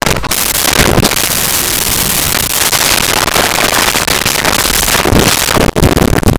Lion Snarl Growl 01
Lion Snarl Growl 01.wav